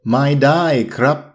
∧ maii ∧ daii / krabb